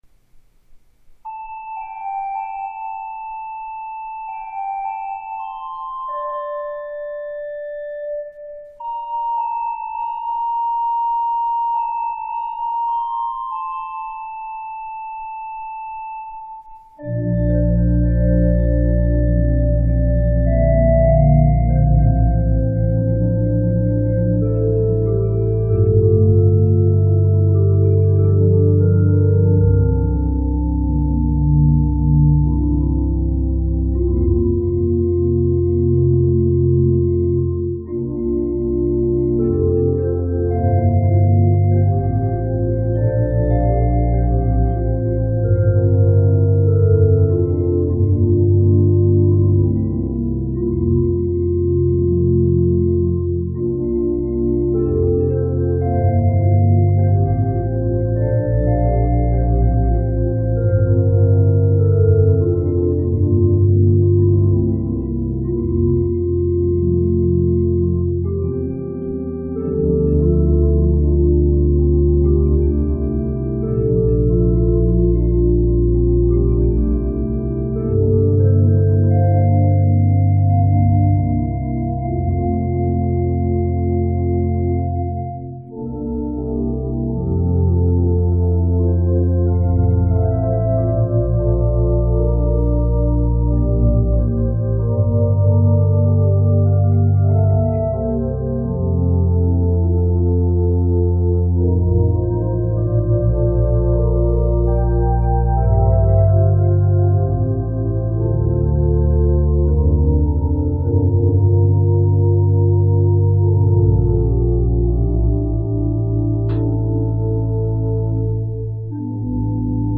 I try to write my compositions for the beginning to intermediate LDS organist.
Brother James’ Air is a simple melody composed by the Scottish hymn writer James McBain.
In keeping with the simple nature of this beautiful tune, I’ve tried to present it with minimal embellishment.